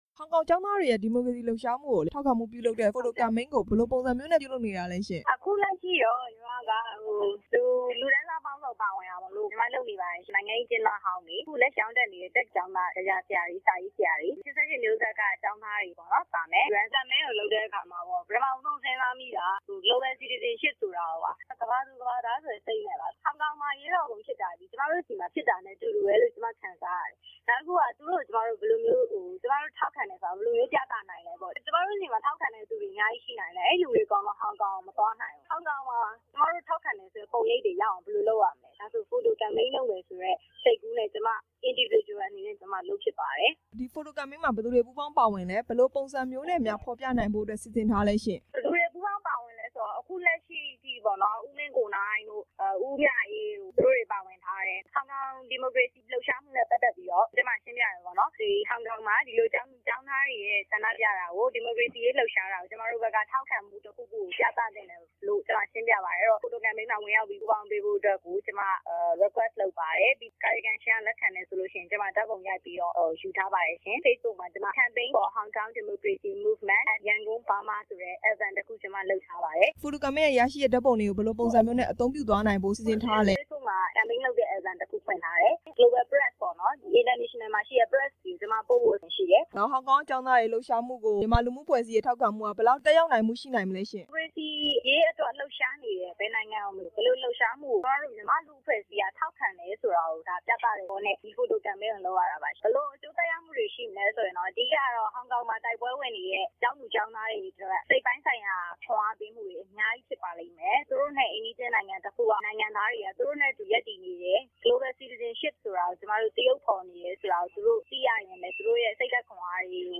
ဟောင်ကောင် ဒီမိုကရေစီအရေး ထောက်ခံလှုပ်ရှားမှုအကြောင်း မေးမြန်းချက်